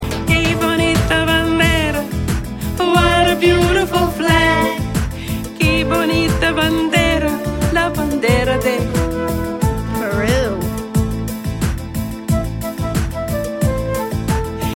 Traditional Song, Puerto Rico